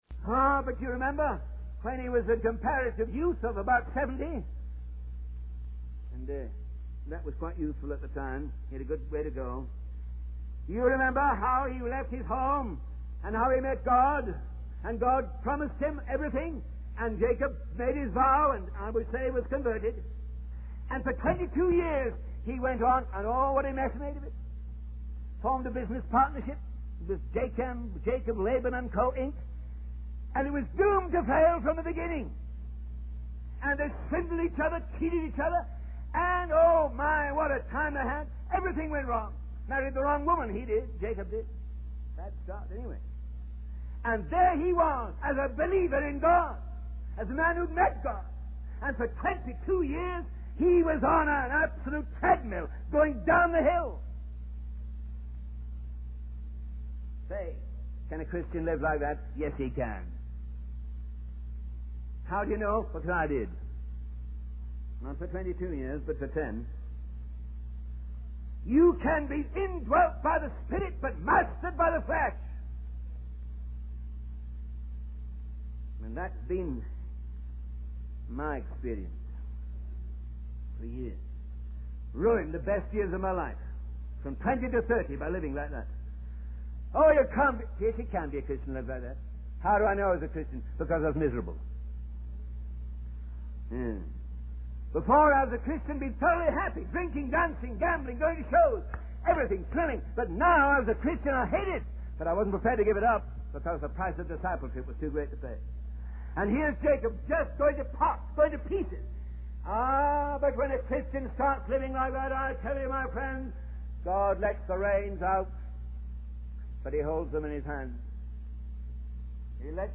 In this sermon, the preacher emphasizes the importance of revival in the church. He highlights the need for the Holy Spirit to bring life and vitality to the church and its ministries.